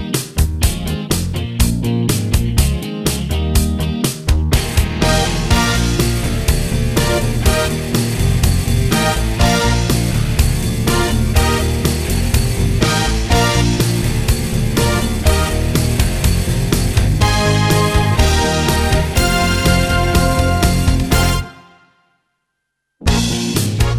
One Semitone Down Pop (1980s) 3:53 Buy £1.50